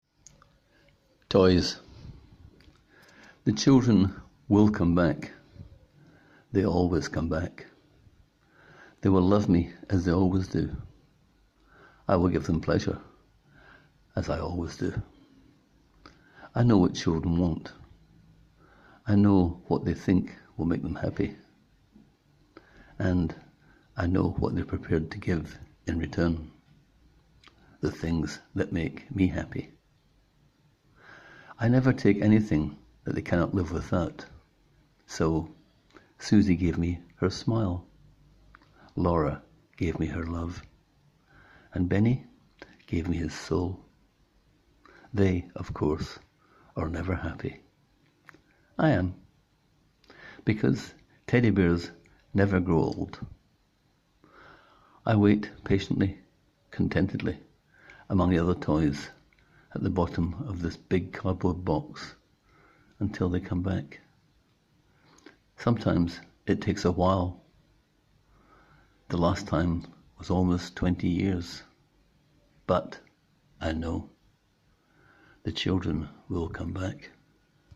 Click here to hear the author read this very short story: